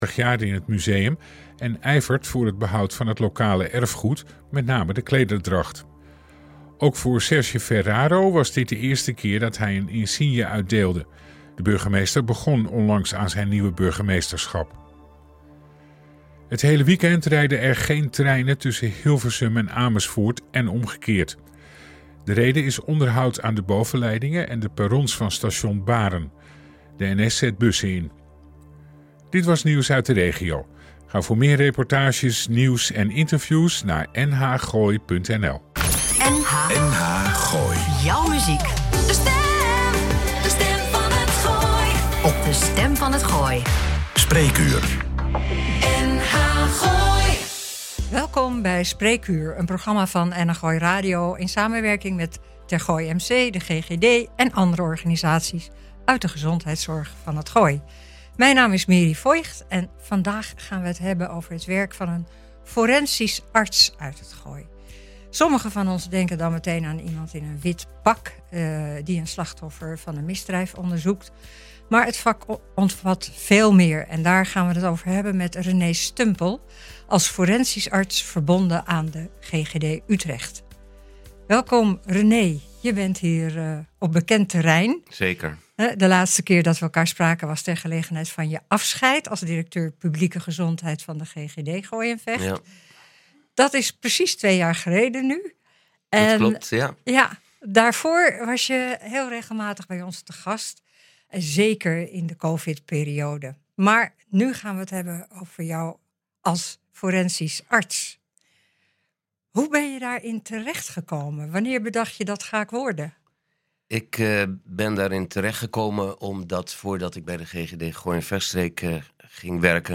NH Gooi Spreekuur - In gesprek